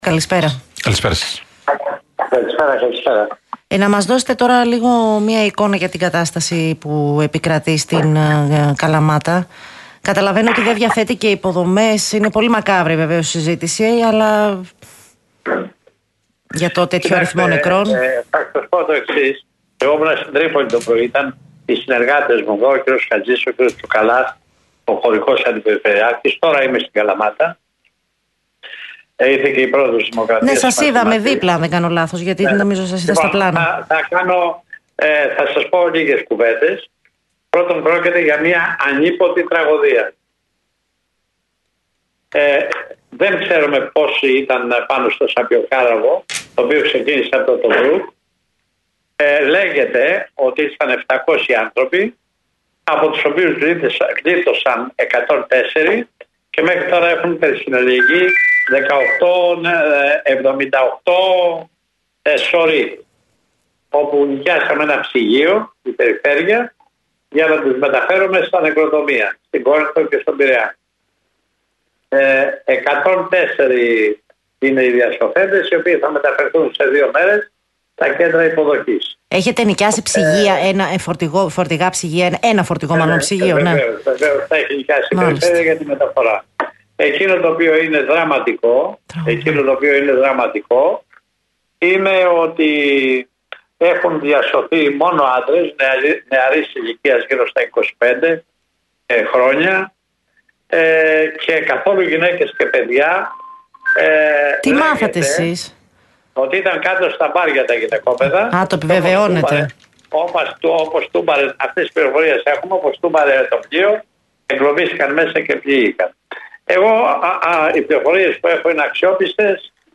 Σε ερώτηση αν έχει πληροφορηθεί τι έχει συμβεί στις γυναίκες και τα παιδιά ο περιφερειάρχης Πελοποννήσου απάντησε: «Ότι ήταν κάτω στα αμπάρια τα γυναικόπαιδα και ότι, όπως τούμπαρε το πλοίο εγκλωβίστηκαν μέσα και πνίγηκαν».